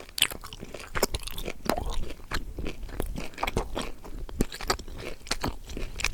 action_eat_0.ogg